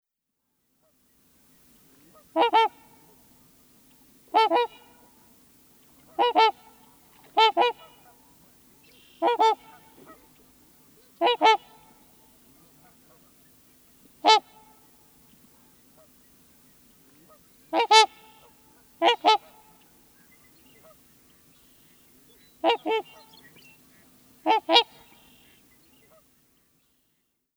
Звуки лебедей
Вы можете слушать или скачать их голоса, шум крыльев и плеск воды в высоком качестве.